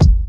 Kick (Fancy Clown).wav